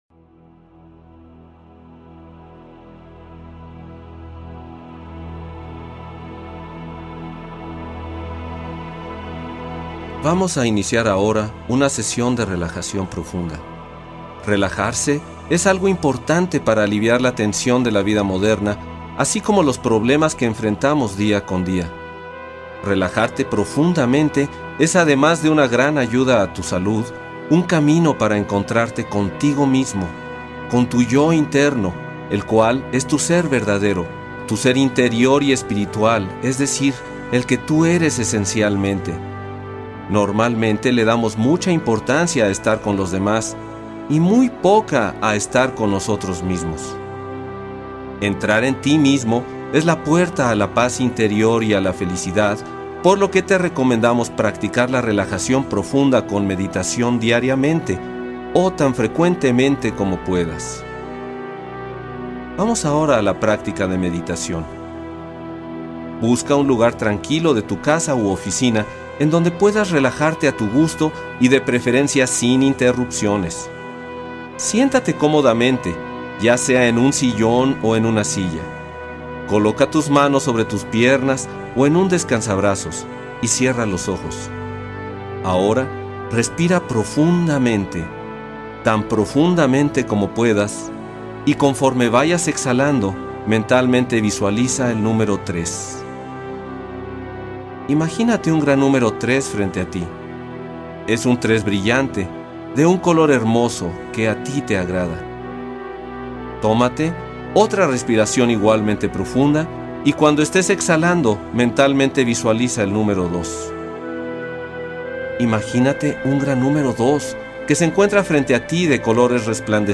Meditación y Relajación